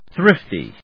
thrift・y /θrífti/
• / θrífti(米国英語)